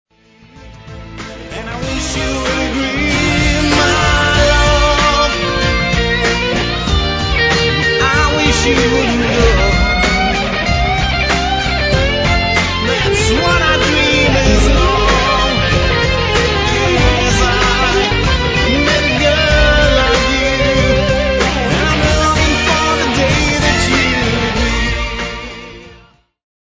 latin akustična gitarska tema